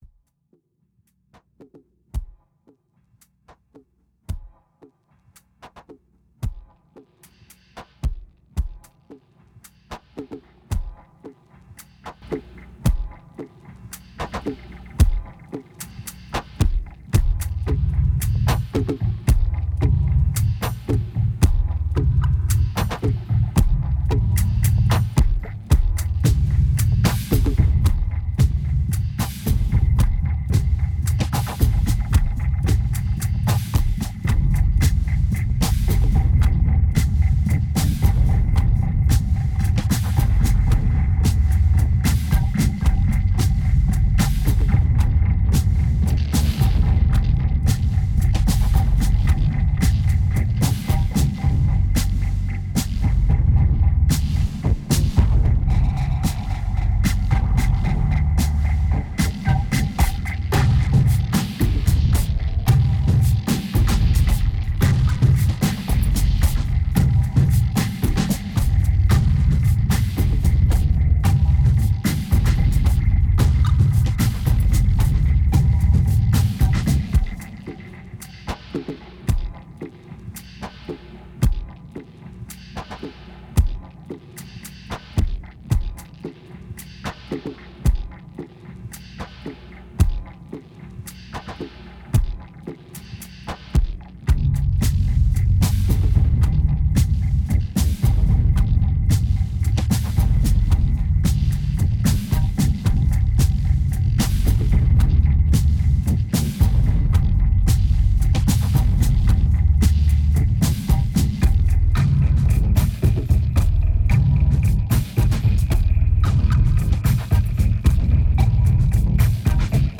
2205📈 - -34%🤔 - 112BPM🔊 - 2009-04-24📅 - -309🌟